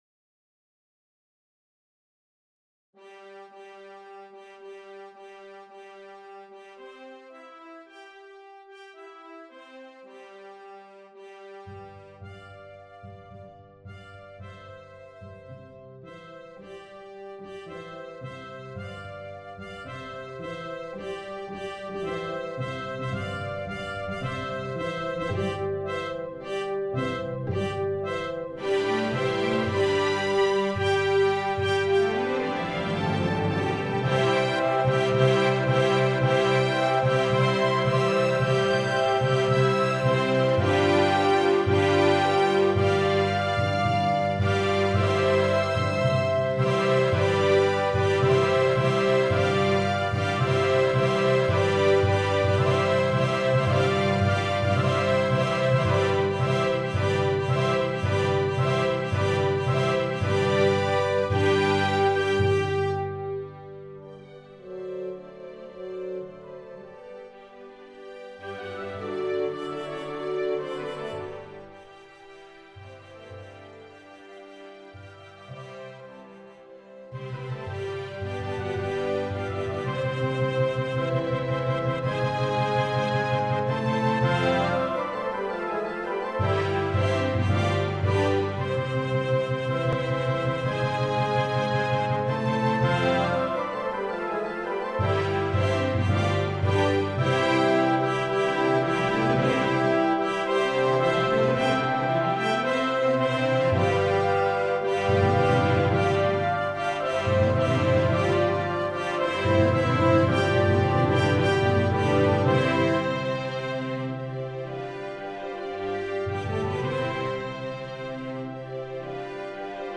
En ce qui concerne les fichiers Midi, ce sont des musiques au son électronique, qui sont créés - on dit "séquencés" - par des musiciens qui en deviennent propriétaires. Ce n'est donc pas un son issu d'un orchestre, mais le résultat est souvent surprenant.
Trait Musique de scène
Voilà les instruments de la marche : 2 flûtes, 2 hautbois, 2 clarinettes, 2 (quatre) cornes, 2 trompettes, percussions et codes.